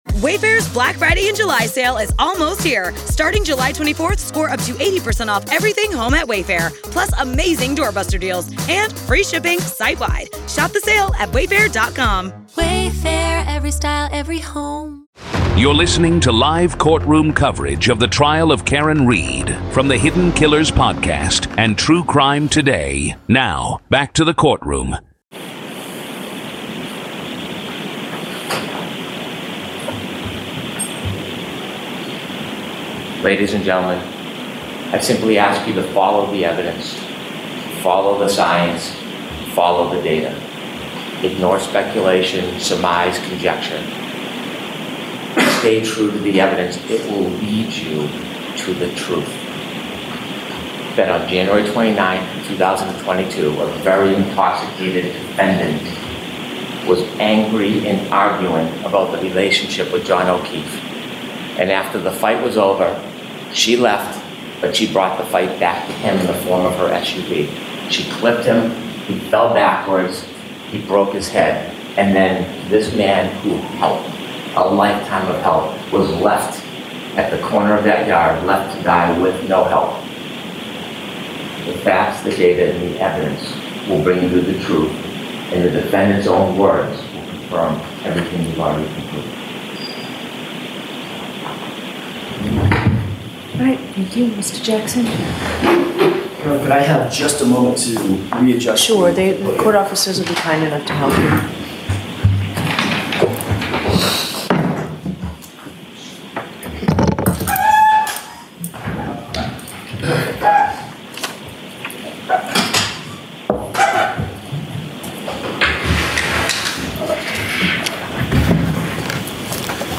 This is live audio from the courtroom in the high-profile murder retrial of Karen Read in Dedham, Massachusetts.